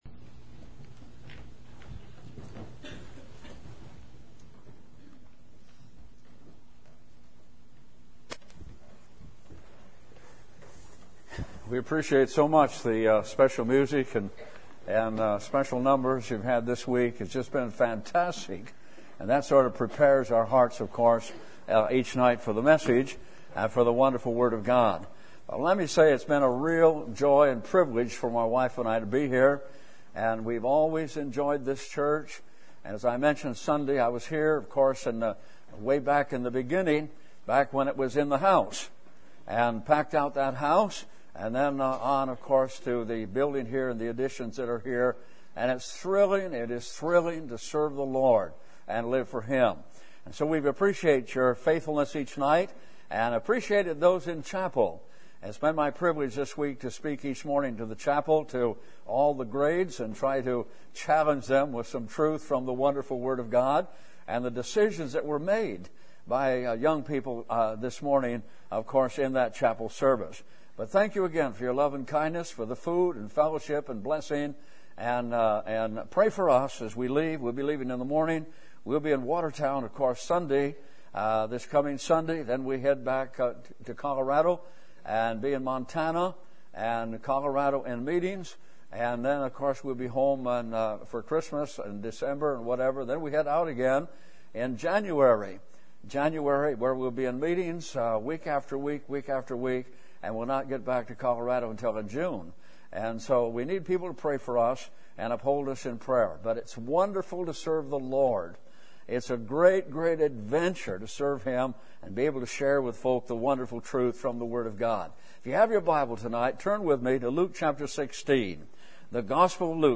Service Type: Revival Meetings